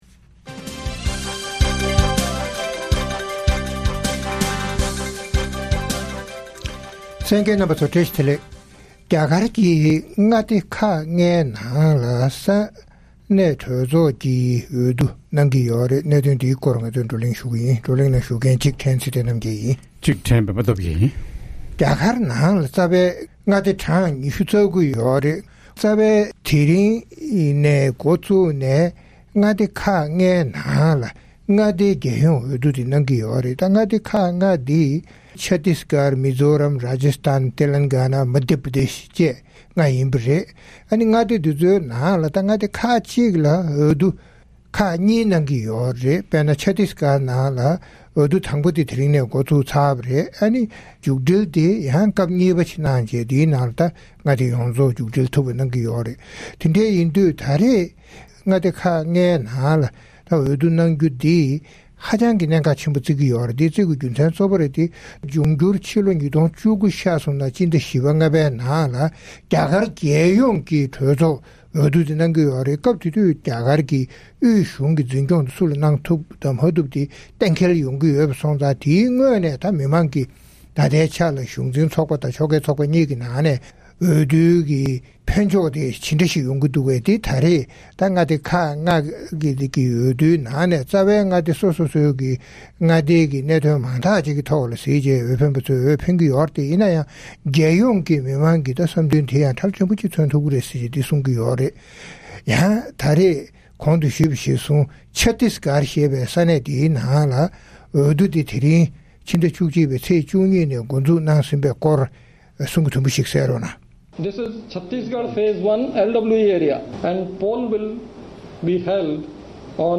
མངའ་སྡེའི་འོས་བསྡུའི་གྲུབ་འབྲས་ཀྱིས་རྒྱལ་ཡོངས་འོས་བསྡུར་ཤུགས་རྐྱེན་ཇི་ཐེབས་སོགས་ཀྱི་སྐོར་རྩོམ་སྒྲིག་འགན་འཛིན་རྣམ་པས་བགྲོ་གླེང་གནང་བ་ཞིག་གསན་རོགས་གནང་།།